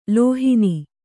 ♪ lōhini